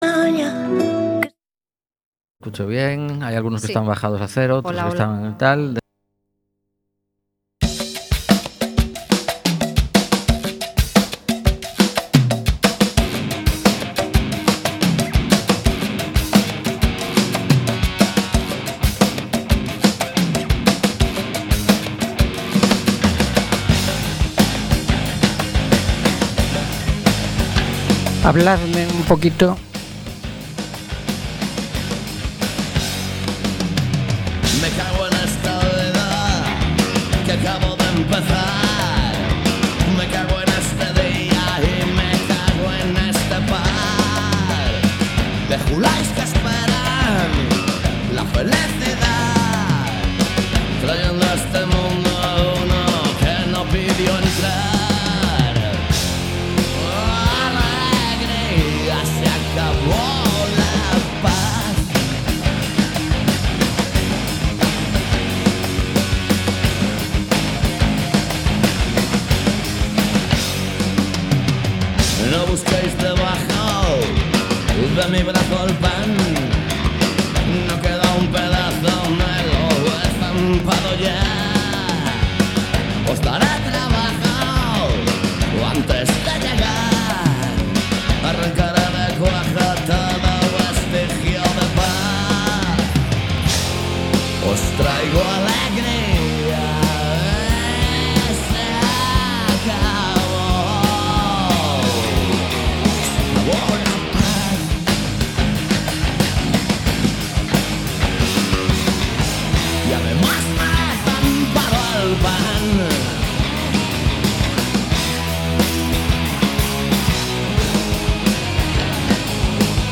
Programa emitido cada mércores de 19:00 a 20:00 horas.